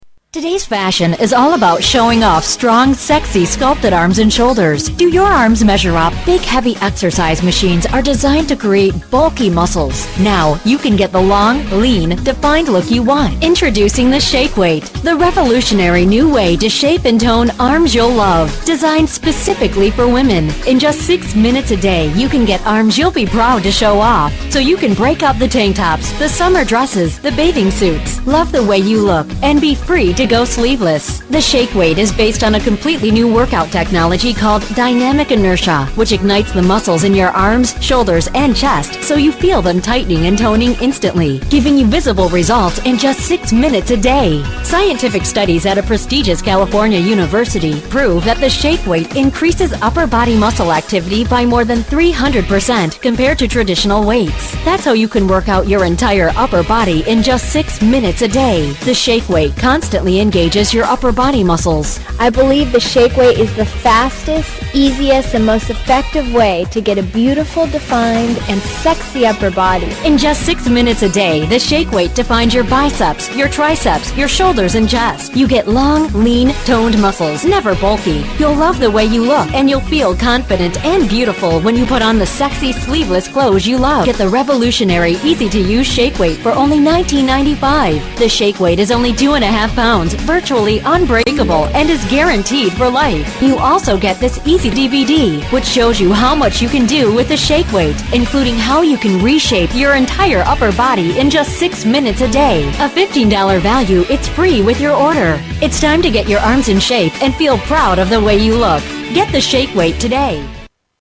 Tags: Shake Weight for Women Shake Weight for Women clips Shake Weight for Women commercial Shake Weight Shake Weight clips